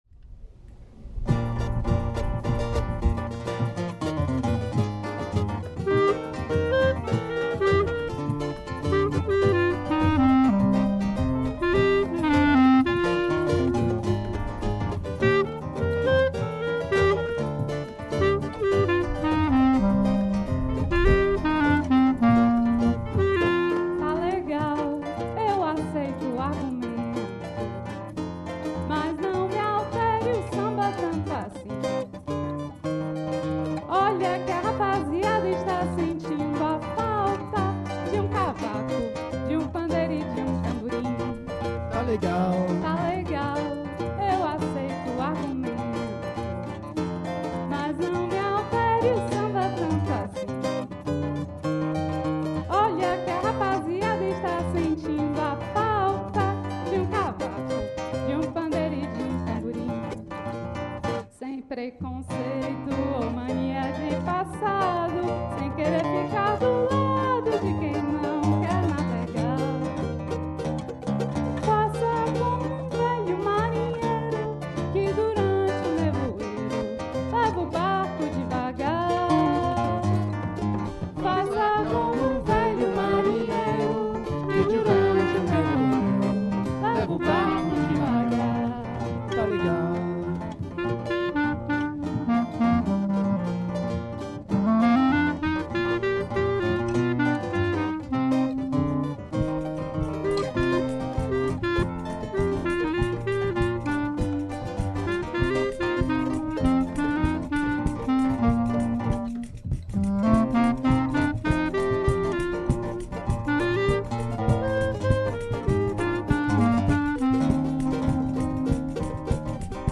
Joué pour la fête de la Musique 2024 au CE TAS